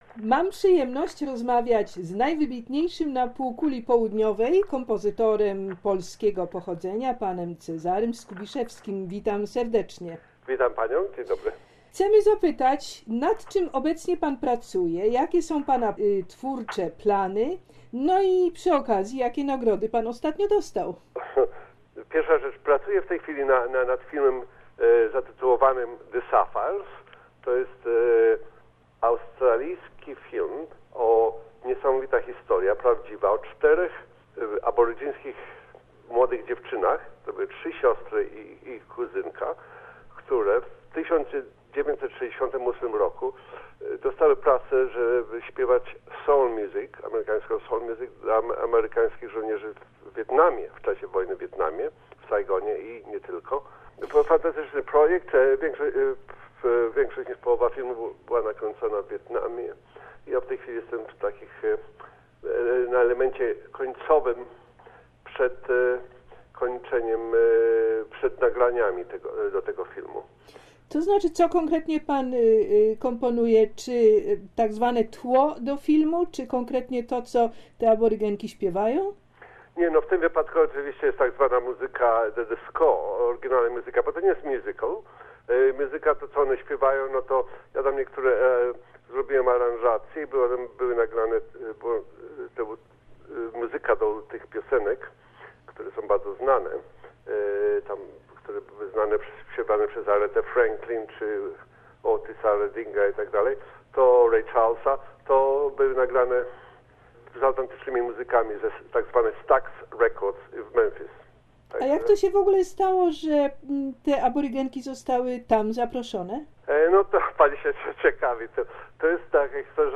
W czasie rozmowy telefonicznej Pan Cezary opowiadał o swoich najnowszych kompozycjach filmowych, o nagrodach i o swoich planach na przyszłość.